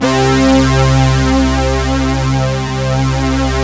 Index of /90_sSampleCDs/Sound & Vision - Gigapack I CD 2 (Roland)/SYN_ANALOG 1/SYN_Analog 2